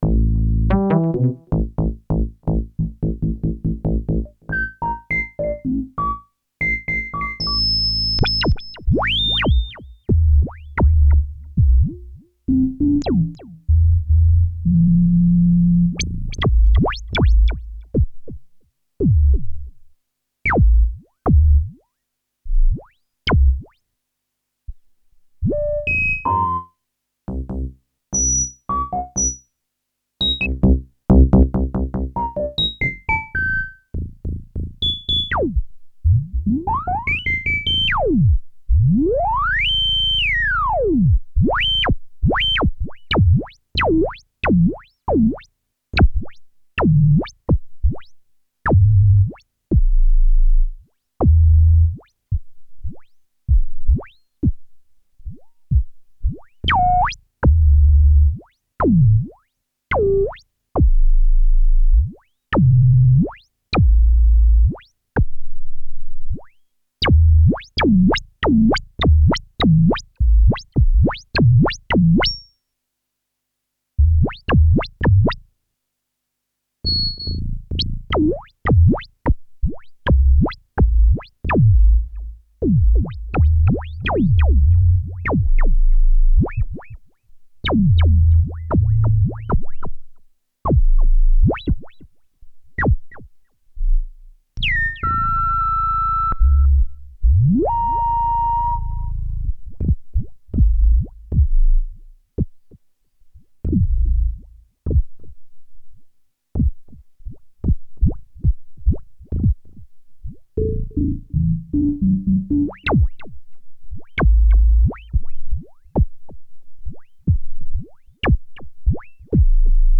Вот как звучит самоосцилляция в более раннем ромплере Yamaha SY85 (1992) Завалялся старый пример на винте.
Здесь всё же больше акцент на низких звуках. Вложения Yamaha SY85 - фильтр, самоосцилляция, свип.mp3 Yamaha SY85 - фильтр, самоосцилляция, свип.mp3 6,5 MB · Просмотры: 1.031